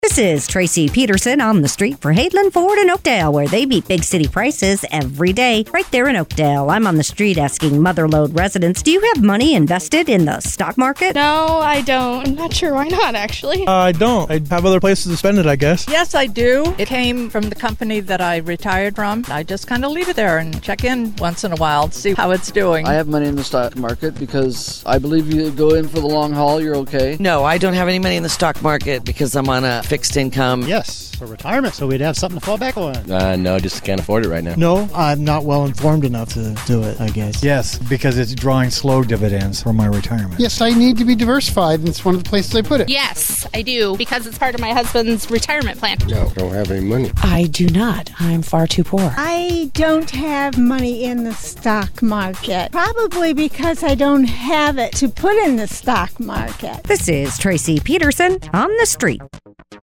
asks Mother Lode residents, “Do you have money invested in the stock market?”